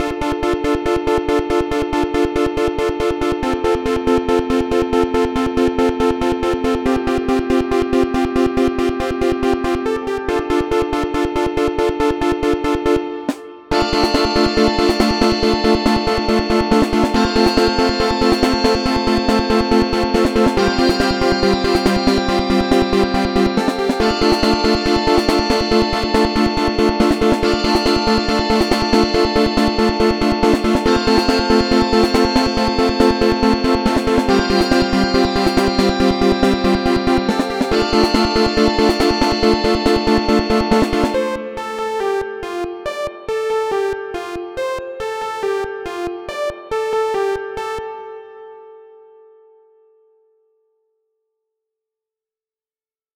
- Electronic